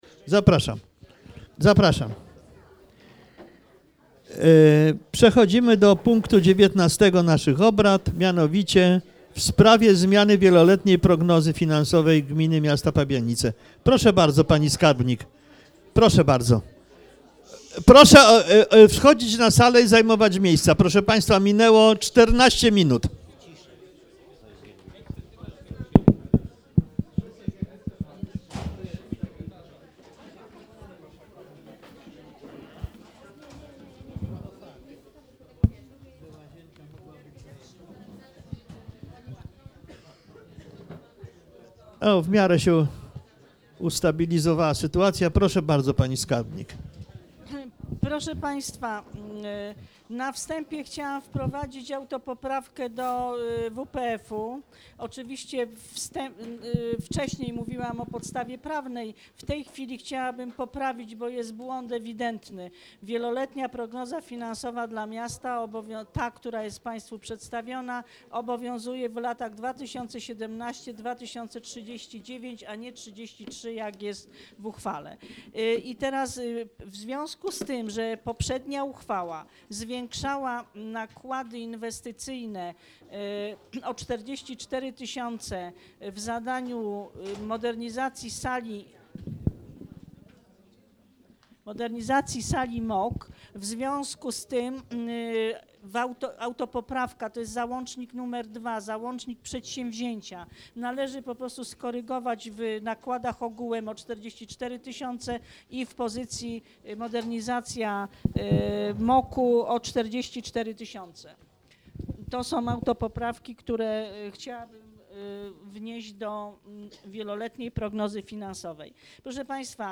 XLVII sesja Rady Miejskiej w Pabianicach - 25 października 2017 r. - 2017 rok - Biuletyn Informacji Publicznej Urzędu Miejskiego w Pabianicach